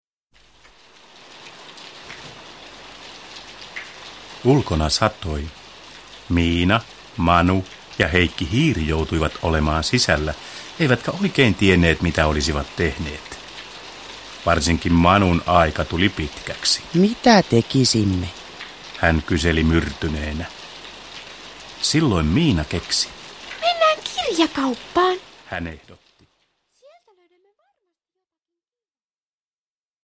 Miina ja Manu sadun maailmoissa – Ljudbok – Laddas ner